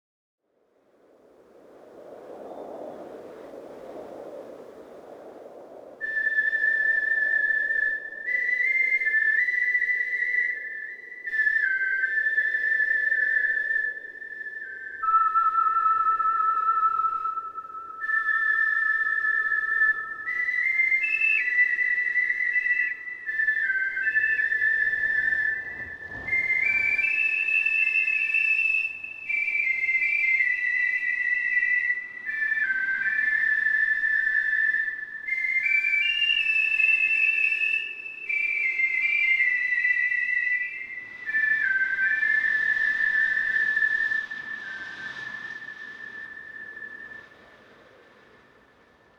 Whistle-and-wind.mp3